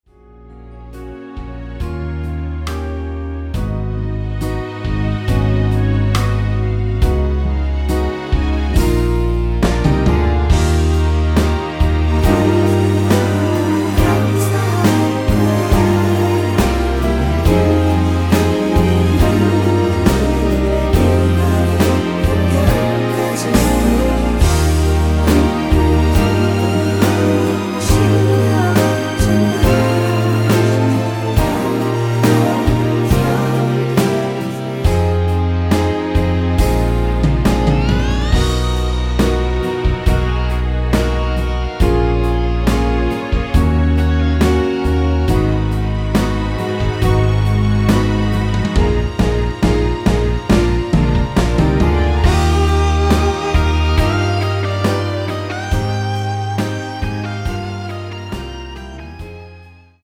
대부분의 여성분이 부르실수 있는키로 제작 하였습니다.
원키에서(+2)올린 코러스 포함된 MR입니다.
F#
앞부분30초, 뒷부분30초씩 편집해서 올려 드리고 있습니다.
중간에 음이 끈어지고 다시 나오는 이유는